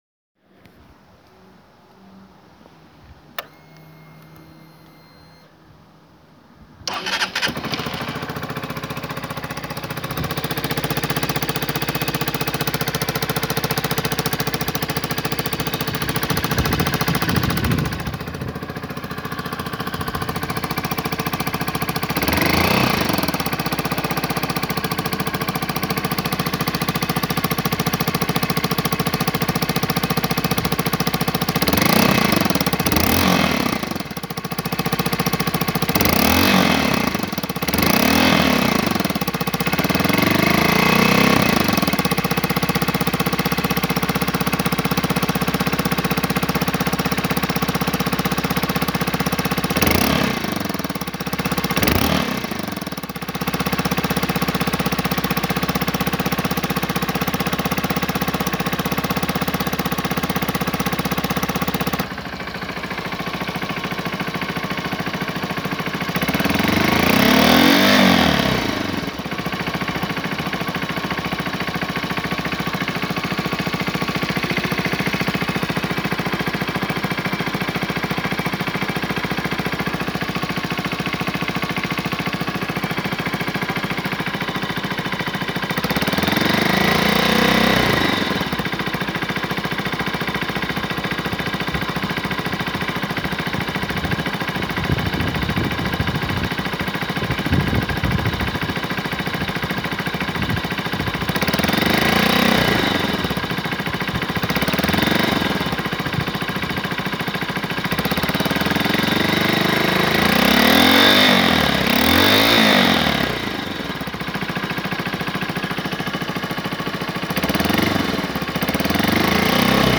• Hlasité "sykavé" zvuky po přidání plynu
Zdravím, u mé Hondy CB125F se začaly objevovat hlasité "sykavé" zvuky vázané na pracovní cyklus motoru. Po nastartování studeného motoru jsou zřetelně slyšet i na volnoběh, po zahřátí motoru se projevují jen pokud přidám plyn.
CB125F sykavé zvuky je nahrávka po startu motoru za studena. Přesně v čase 1:02 je patrno, jak zvuk při běhu na volnoběh najednou zmizí, a dále se objevuje jen po přidání plynu.
cb125f_sykave_zvuky.m4a